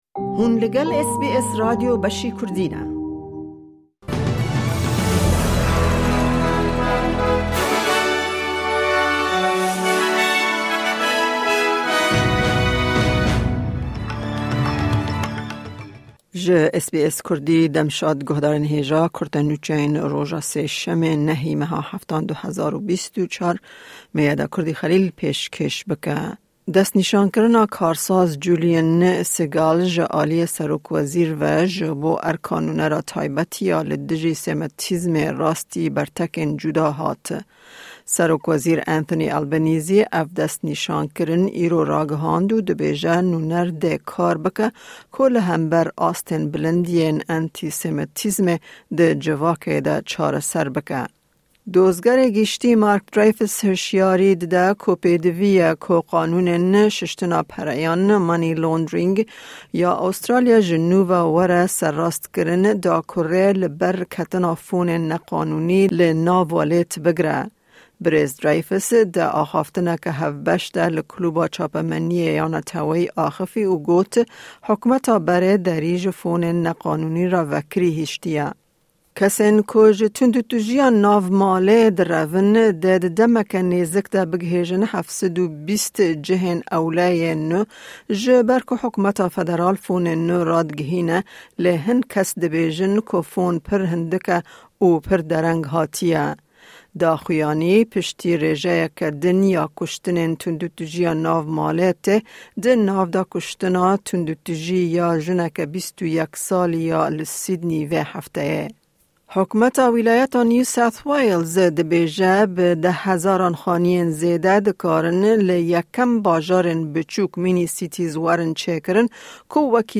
Kurte Nûçeyên roja Sêşemê 9î Tîrmeha 2024